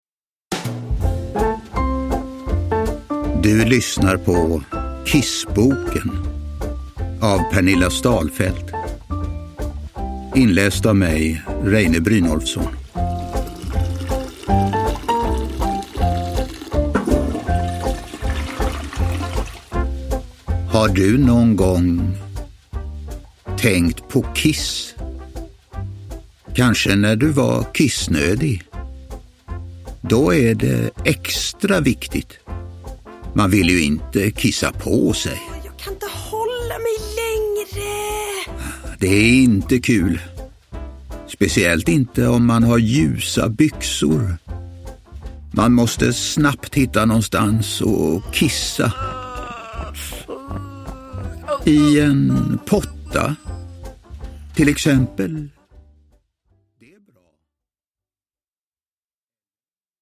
Kissboken – Ljudbok – Laddas ner